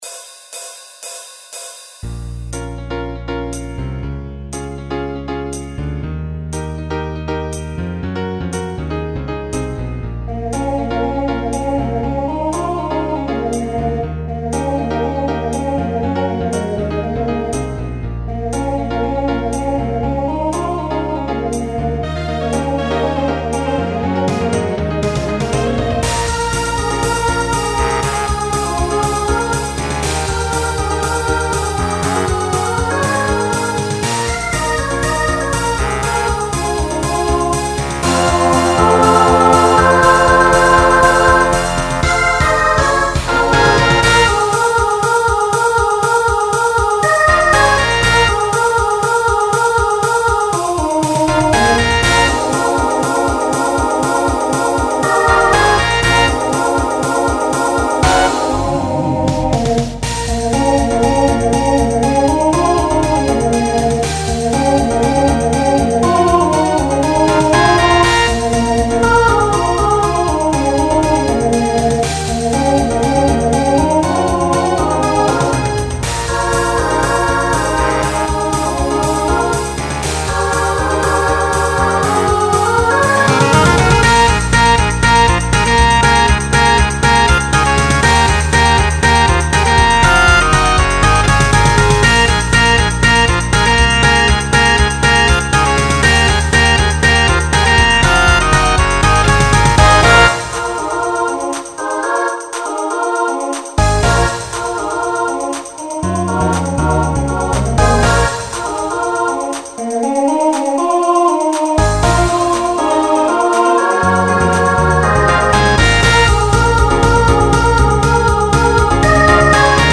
Genre Pop/Dance
Transition Voicing SSA